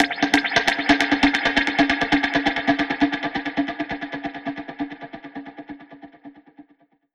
Index of /musicradar/dub-percussion-samples/134bpm
DPFX_PercHit_E_134-03.wav